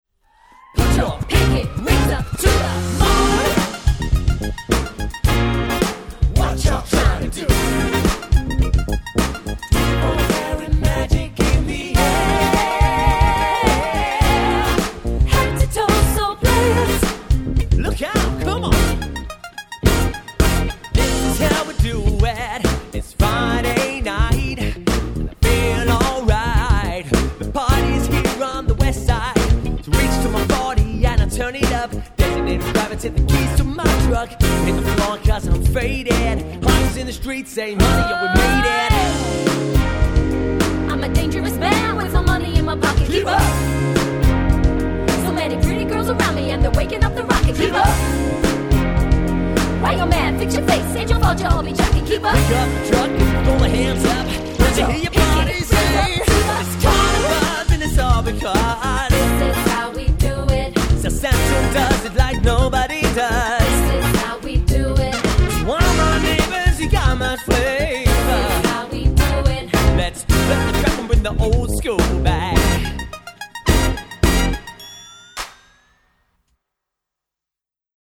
Versatile and Eclectic Function Band
(12-piece)